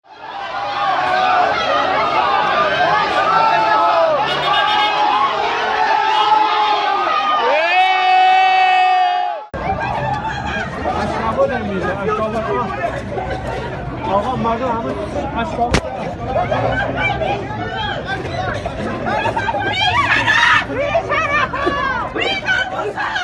The brutal attack on looters of the Alborz Development Institute in Karaj with tear gas